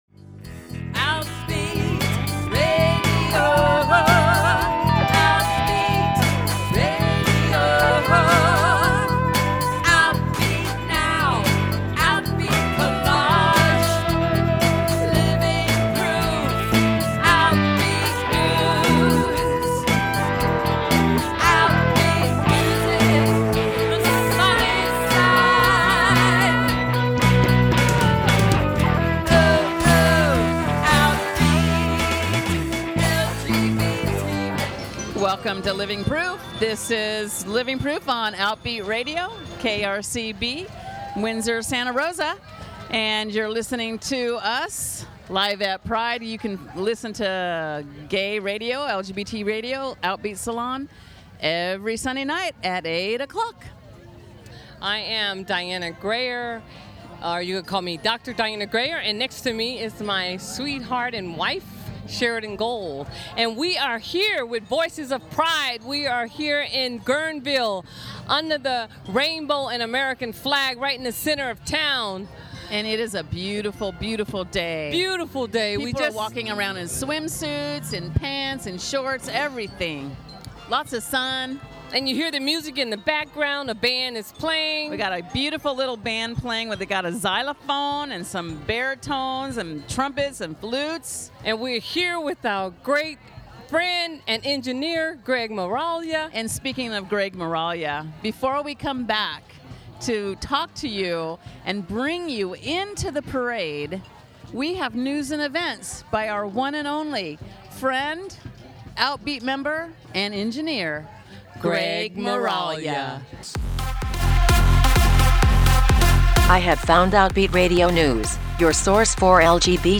Recorded live at the 2014 Sonoma County Pride celebration in Guerneville.